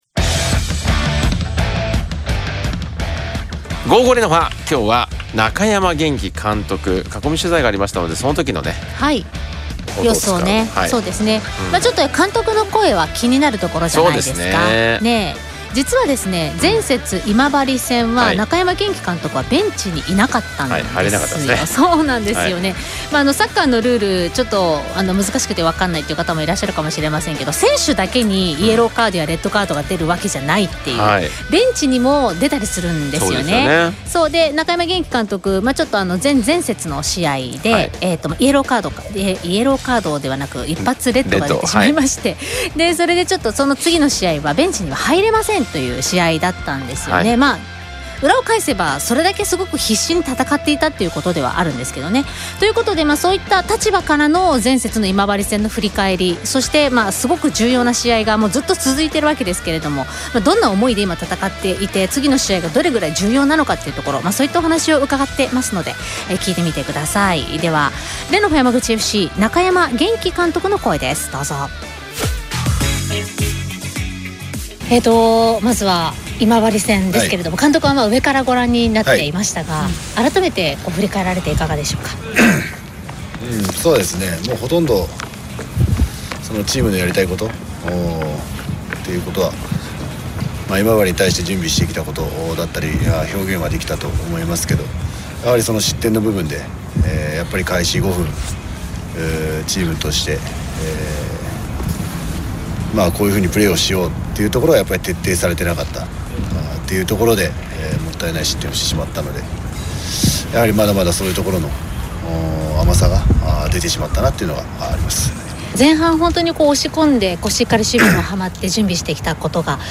（囲み取材音源）